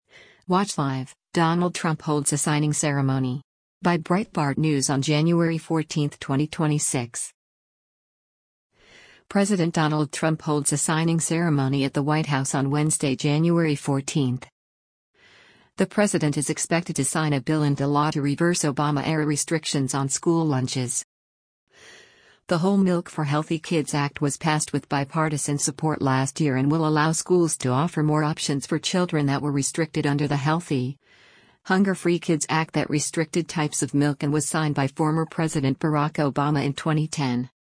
President Donald Trump holds a signing ceremony at the White House on Wednesday, January 14.